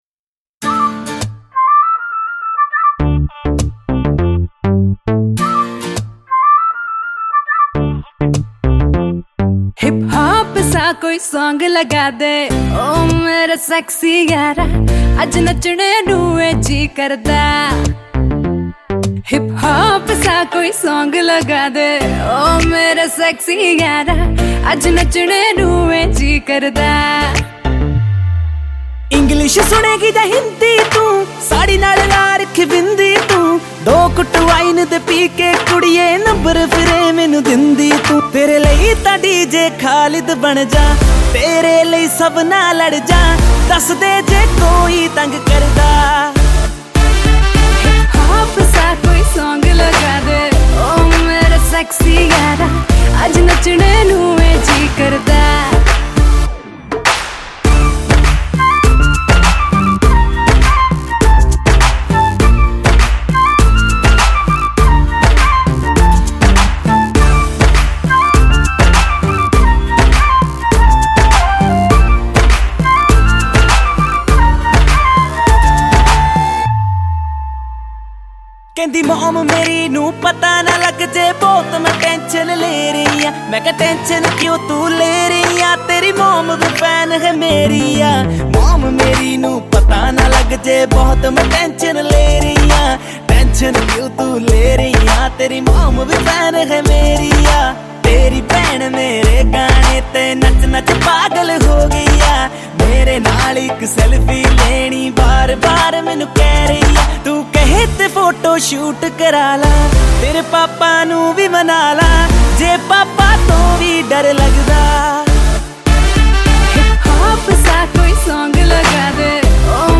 Punjabi Bhangra
Indian Pop